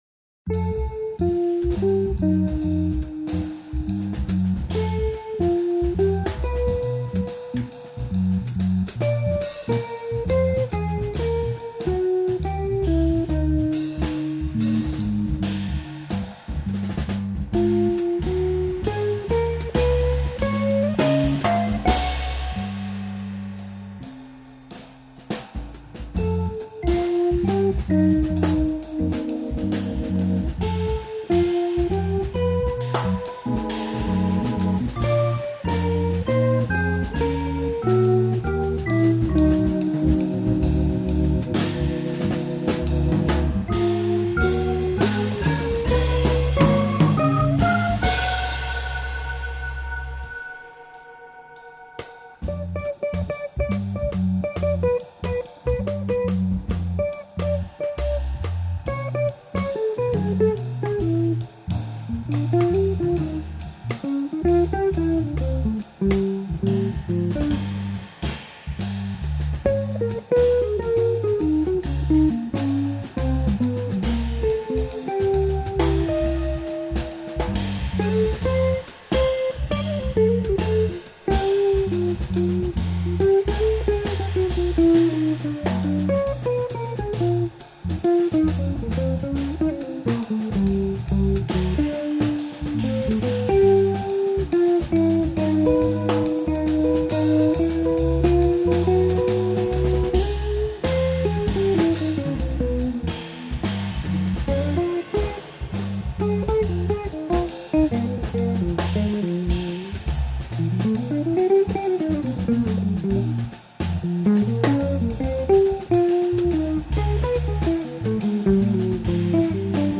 Comme promis, j'ai déposé un autre extrait, une compo ce coup-ci, "Simple 5", qui comme son nom l'indique est un morceau en 5/4 avec une p'tite mélodie qui m'a semblée s'imposer avec tellement d'évidence que le nom en découle.
Il a choisi là une musique plus 'accessible', voire consensuelle.
L'atmosphère un peu tendue est, à mon avis, davantage liée au 5/4 qu'à la grille.
Avec de délicates interventions d'orgue à la fin du thème, rien pendant le solo de guitare.
Ca chauffe !
J'ai beaucoup aimé le son de la guitare, effet de phasing qui ferait presque penser qu'il joue sur une cabine en slow.